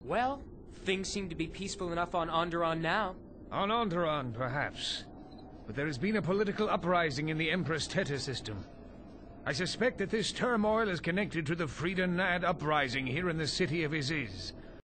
Arca Jeth and Cay Qel-Droma discuss the political uprising of the Empress Teta system